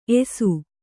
♪ esu